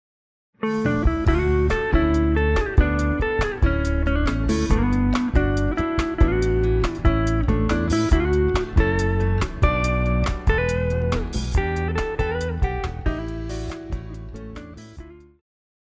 流行
電吉他
樂團
演奏曲
獨奏與伴奏
有節拍器
卻使用大調與小調讓份圍截然不同。